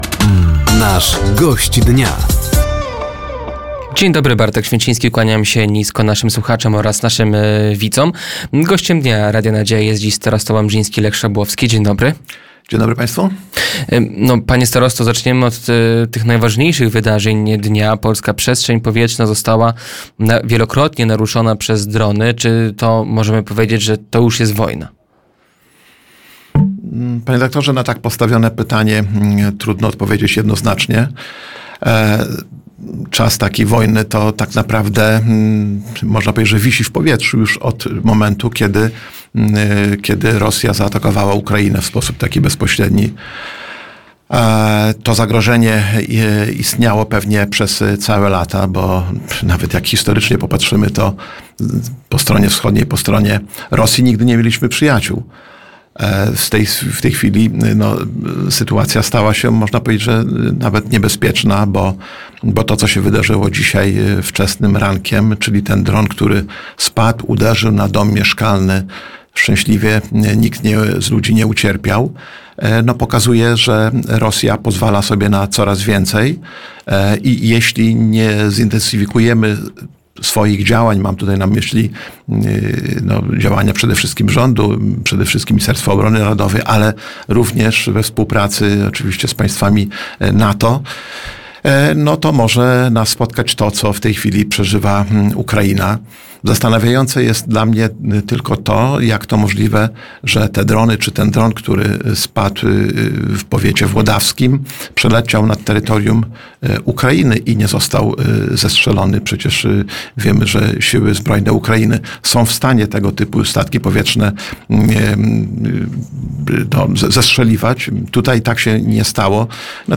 Gościem Dnia Radia Nadzieja był starosta łomżyński Lech Szabłowski. Tematem rozmowy było naruszenie polskiej przestrzeni powietrznej przez rosyjskie drony, czy samorządy są przygotowane na sytuacje kryzysowe, nowa posłanka z Łomży, inwestycje drogowe oraz stypendia starosty.